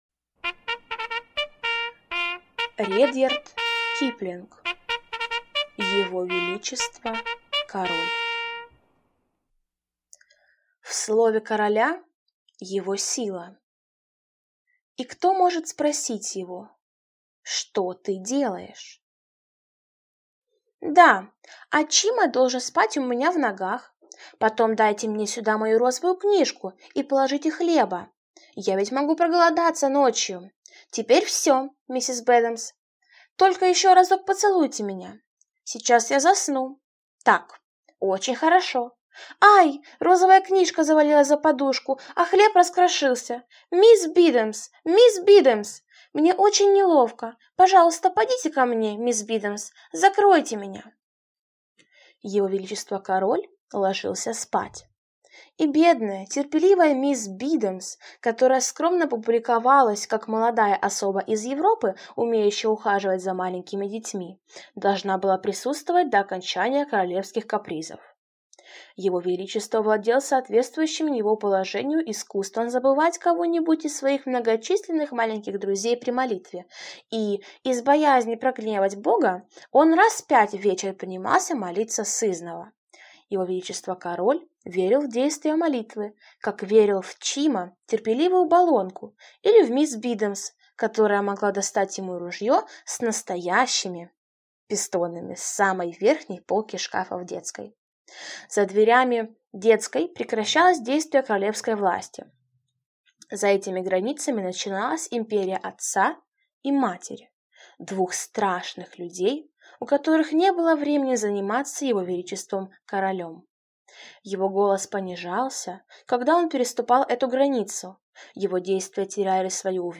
Аудиокнига Его величество король | Библиотека аудиокниг